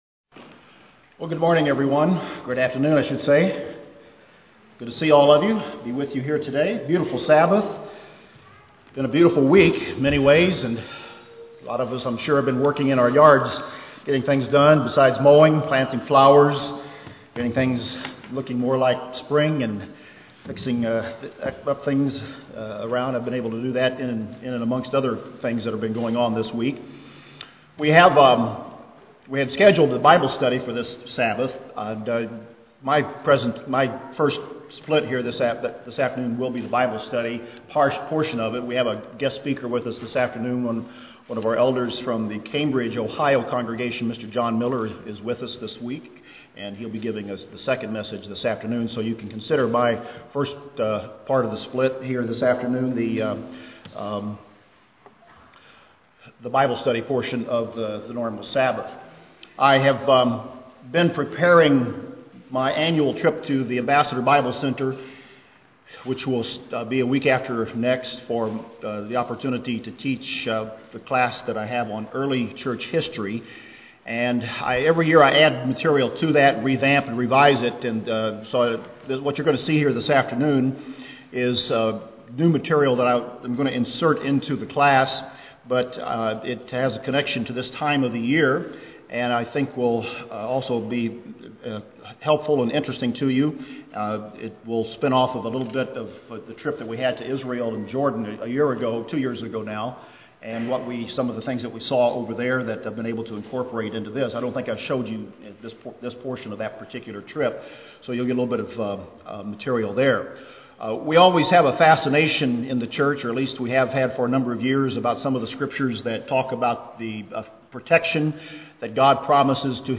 Bible Study on early church history.